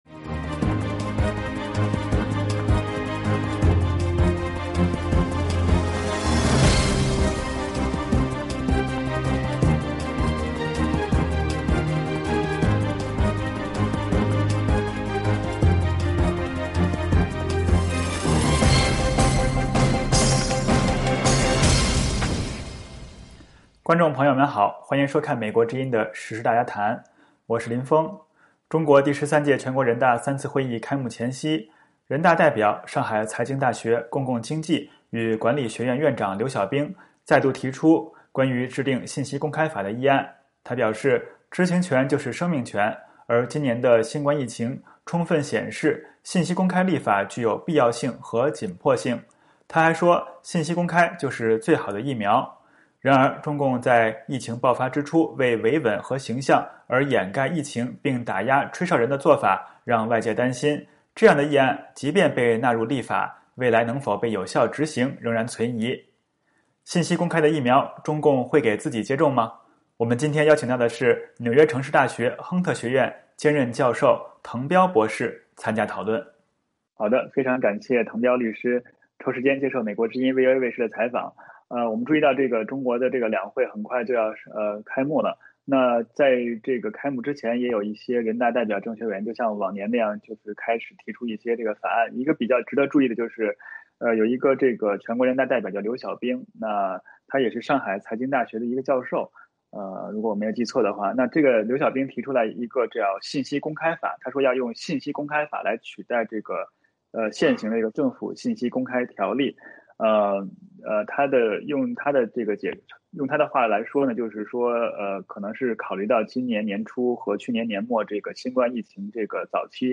信息公开的“疫苗”中共会给自己接种吗？我们今天邀请到的是纽约城市大学亨特学院兼任教授滕彪博士参加讨论。